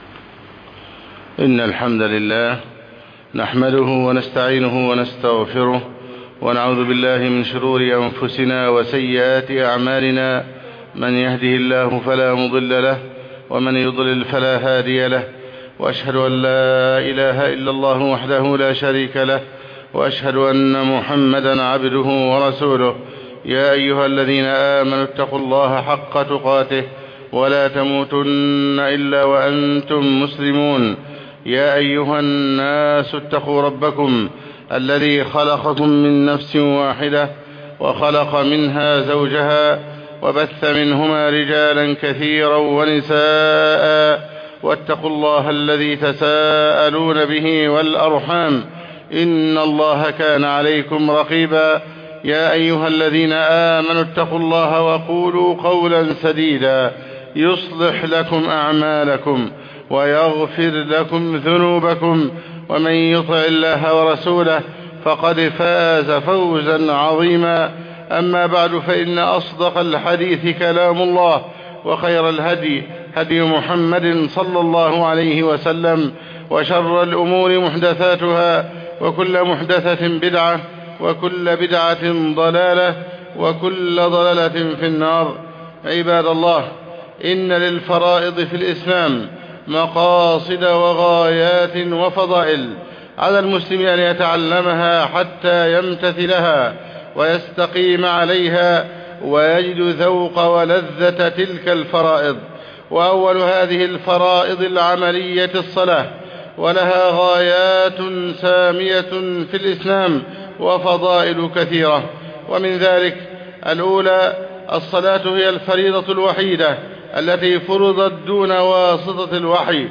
خطب الجمعة والأعياد